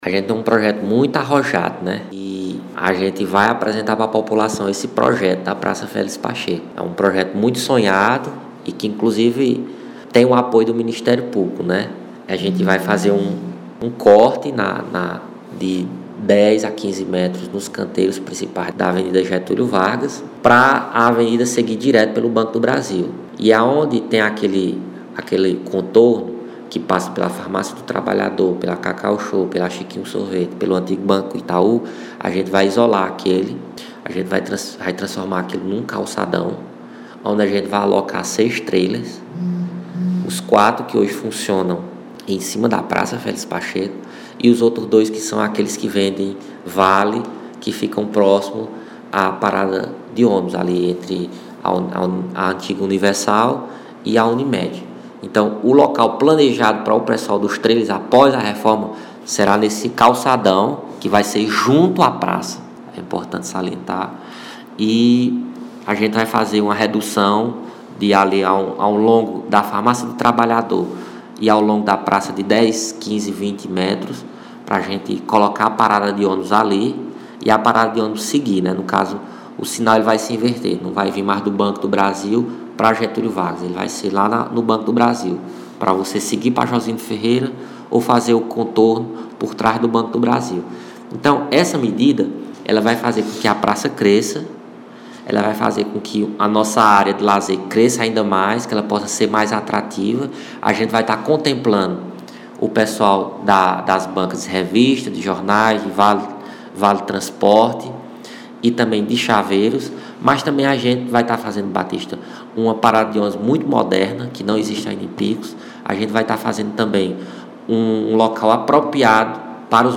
Armínio Carvalho, secretário de meio Ambiente e Recursos Hídricos